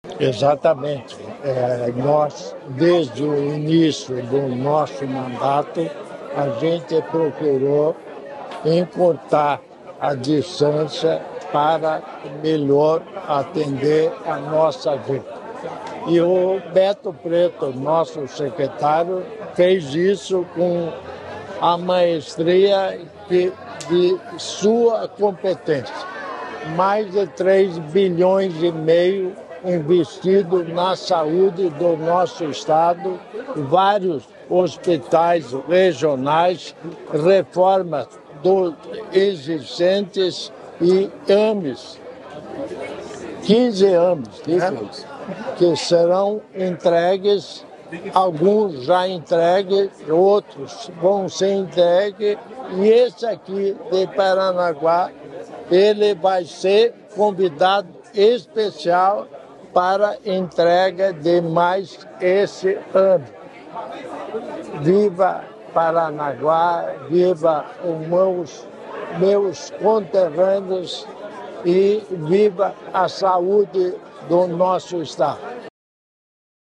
Sonora do vice-governador Darci Piana sobre a inauguração da nova Maternidade de Paranaguá